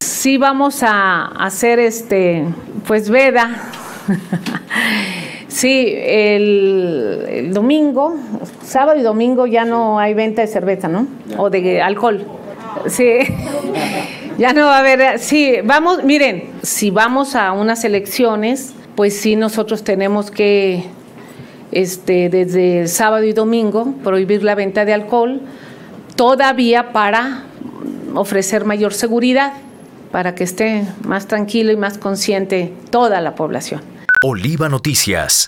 En conferencia de prensa, explicó que con la implementación de dicha medida, buscan que la ciudadanía pueda ejercer su voto de manera tranquila y consciente.